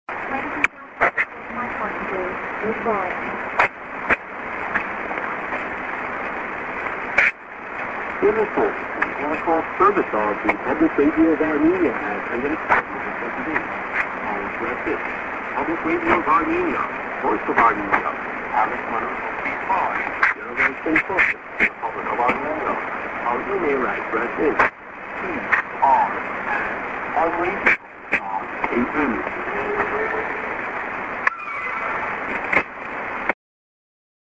prog->ANN(man:ID+SKJ+ADDR)->s/off　だんだんサイドが強くなってきます。